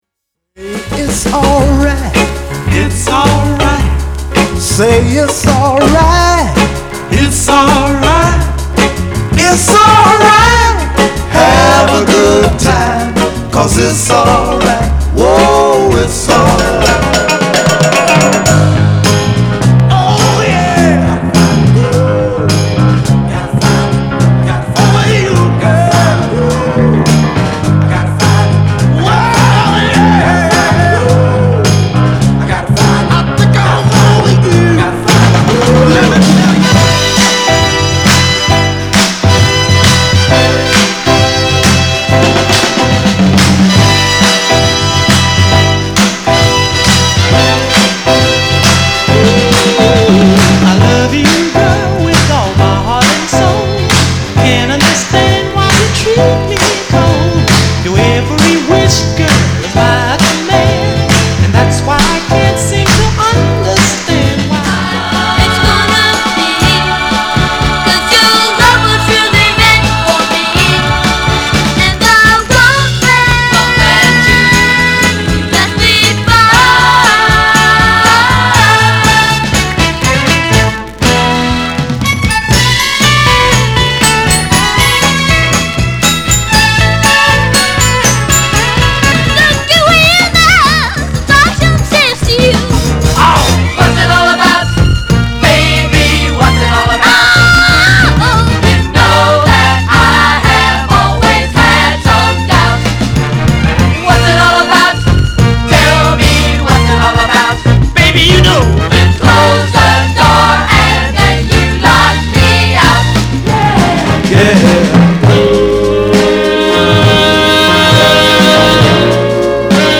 R&B、ソウル